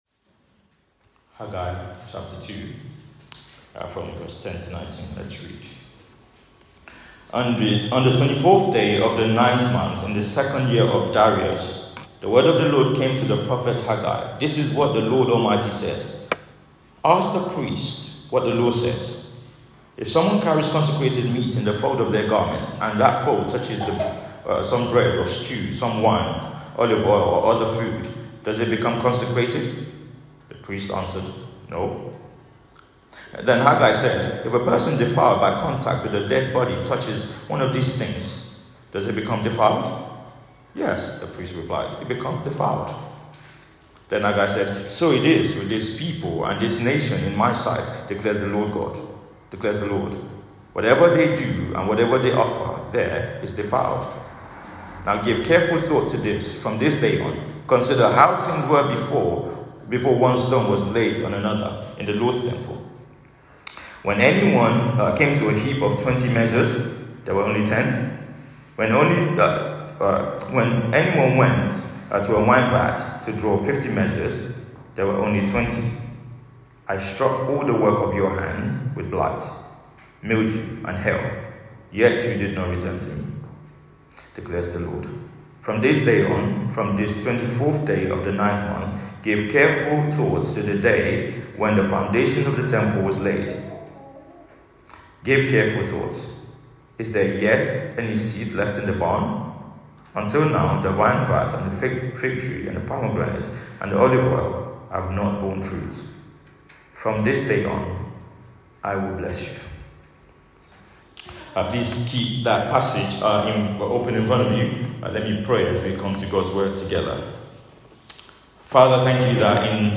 Theme: Building with God's blessing Sermon